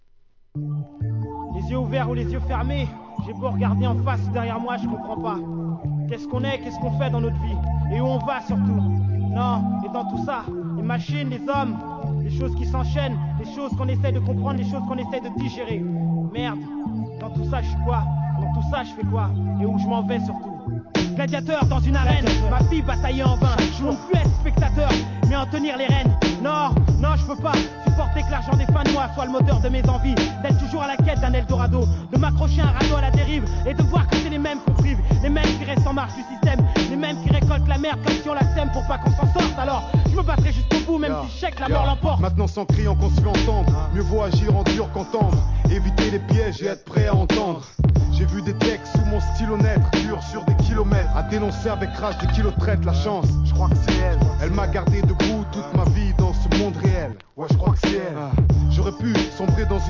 1999年、フランス産HIP HOP!